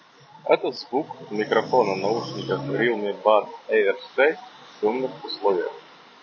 Шумная обстановка — запись на улице возле 6-ти полосной дороги с максимальным шумом автомобилей.
В шумных условиях: